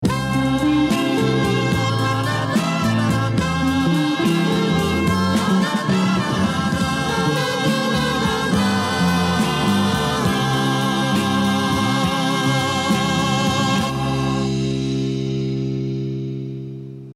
Но по такому маленькому кусочку и без слов, не распознать.